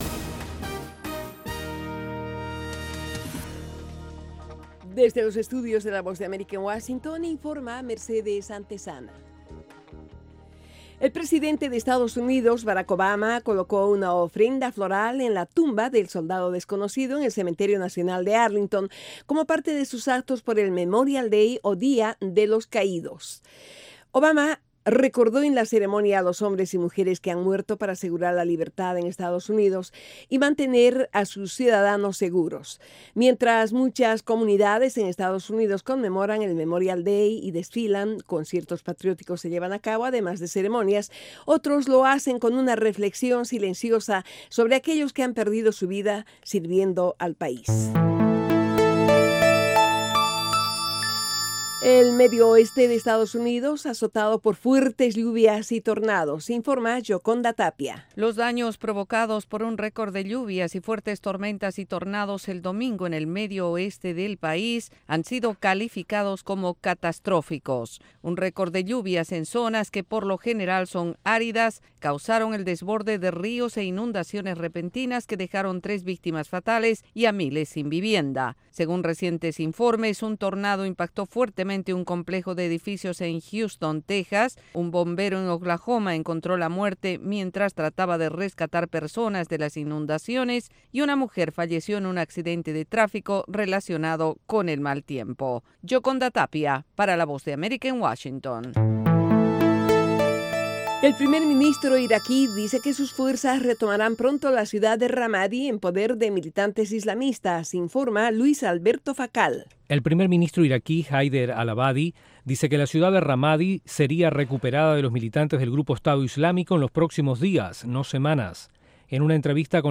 Informativo VOASAT